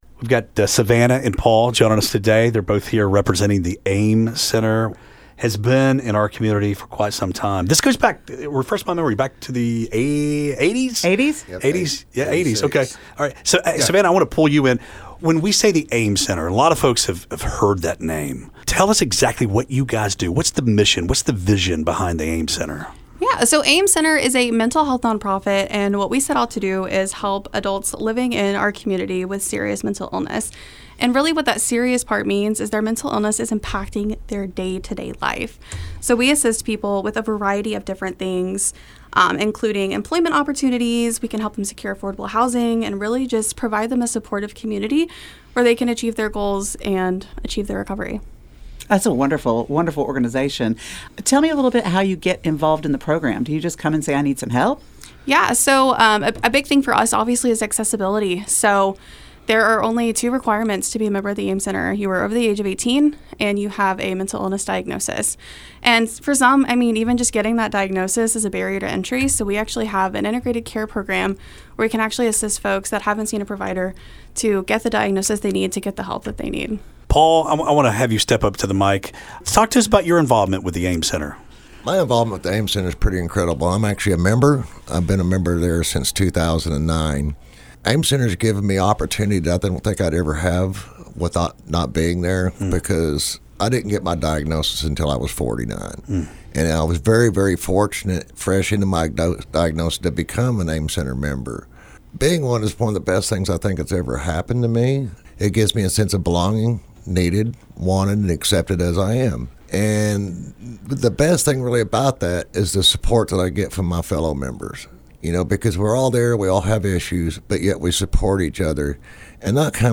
AIM-Center-Full-Interview.wav